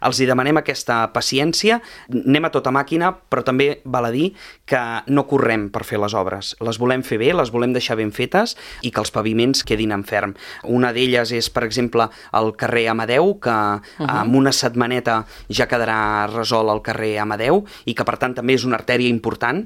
El portaveu de Junts, Josep Grima, ho ha dit a l’entrevista política de Ràdio Calella TV, on ha avançat que els treballs per reforçar el paviment d’Amadeu s’enllestiran la setmana vinent.